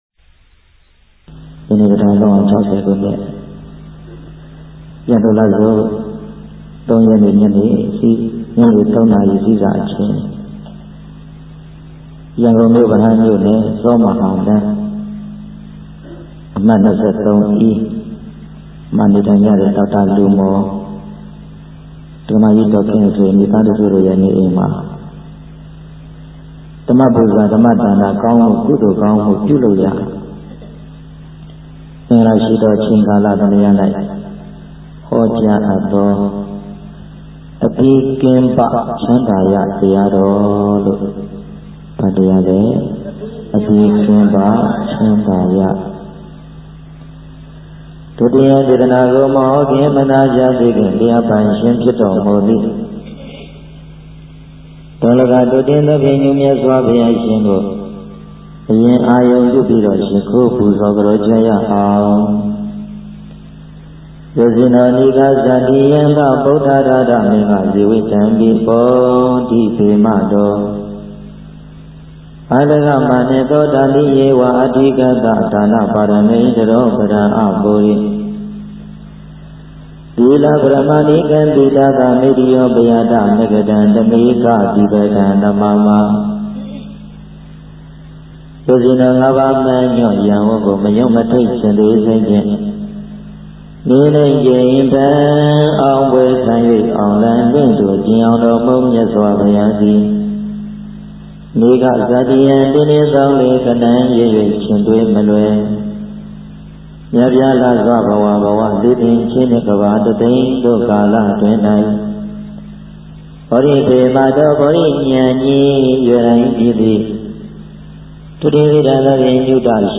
အခွေ(၁) ဓမ္မသဘင်များတွင် ဟောကြားသော တရားတော်များ